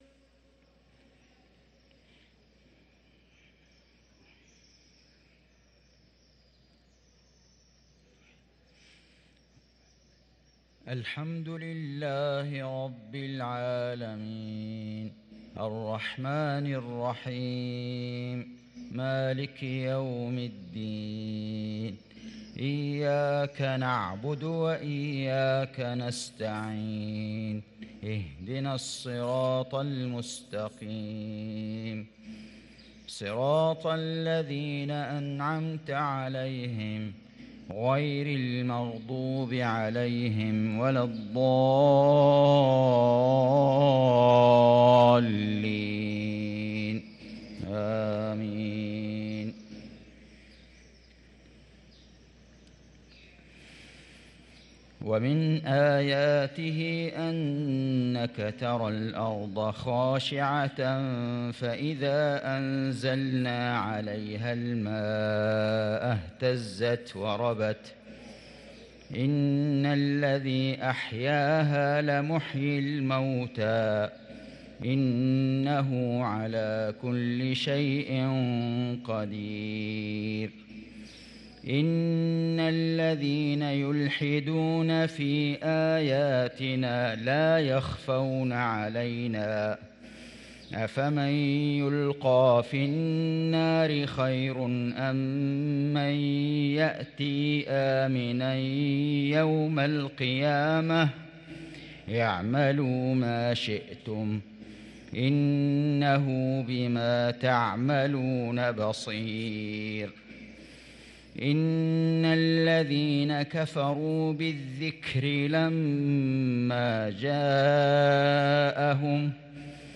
صلاة المغرب للقارئ فيصل غزاوي 5 جمادي الآخر 1445 هـ
تِلَاوَات الْحَرَمَيْن .